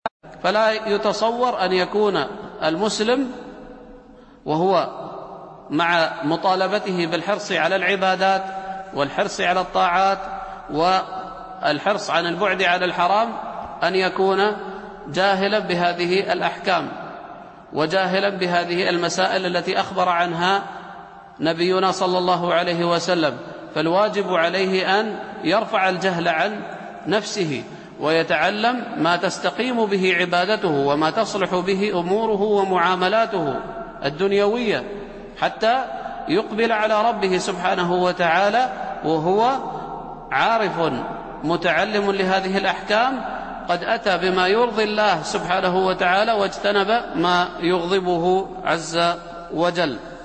التنسيق: MP3 Mono 22kHz 64Kbps (CBR)